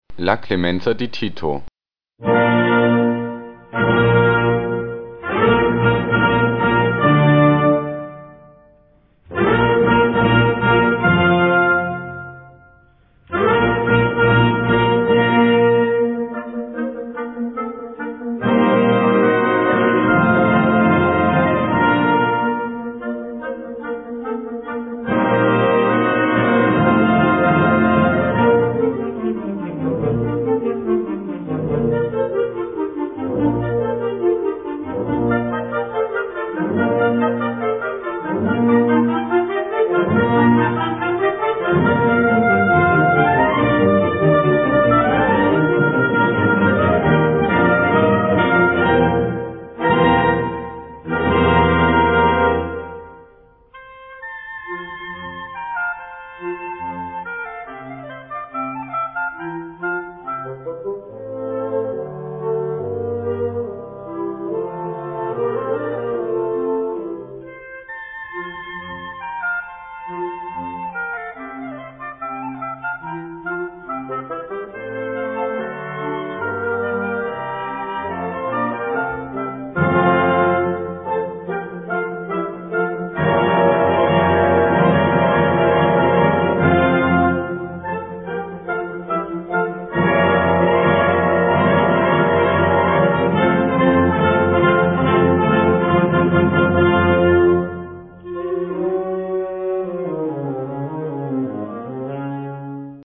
Gattung: Ouvertüre zur Oper
Besetzung: Blasorchester